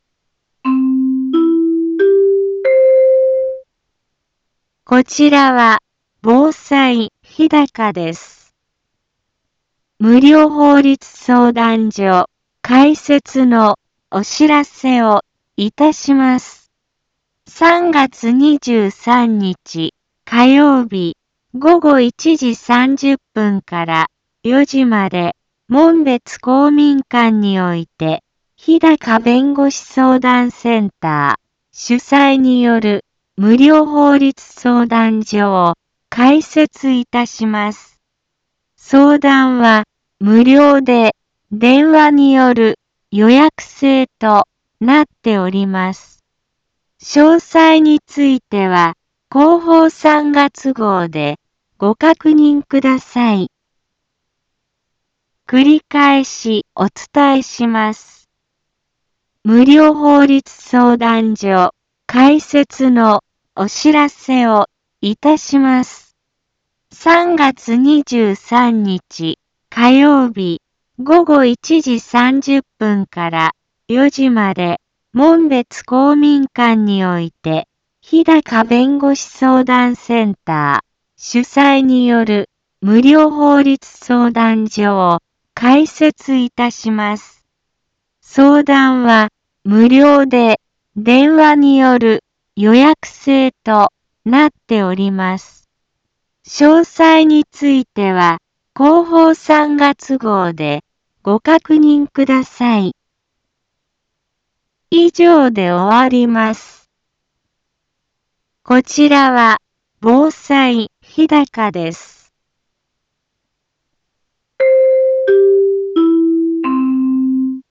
一般放送情報
Back Home 一般放送情報 音声放送 再生 一般放送情報 登録日時：2021-03-16 10:04:08 タイトル：無料法律相談のお知らせ インフォメーション：無料法律相談所開設のお知らせをいたします。 ３月２３日火曜日、午後１時３０分から４時まで、門別公民館において、ひだか弁護士相談センター主催による、無料法律相談所を開設いたします。